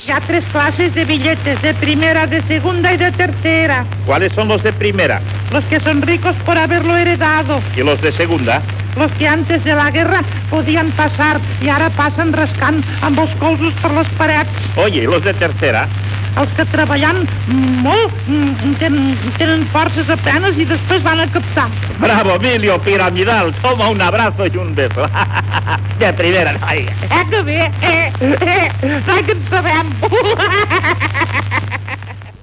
Diàleg amb Miliu sobre el valor dels bitllets, amb esment a la guerra (civil).